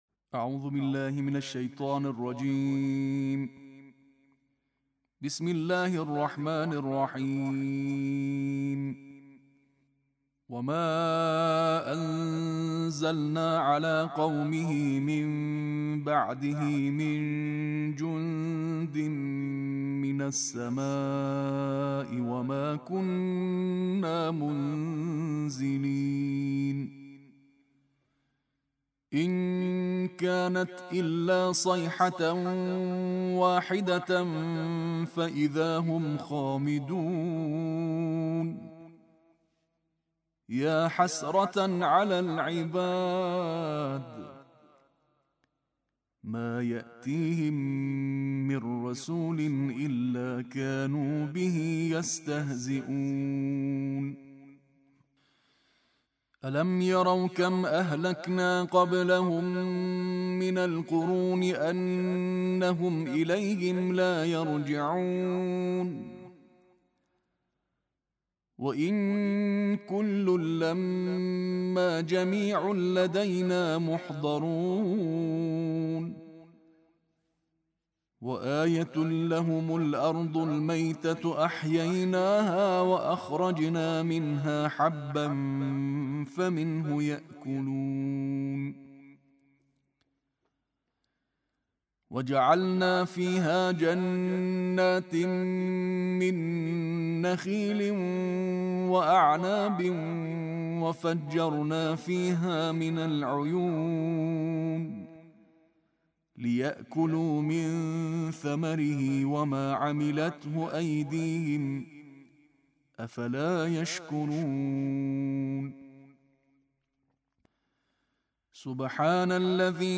Pembacaan Tartil Juz 23 oleh Para Qari Internasional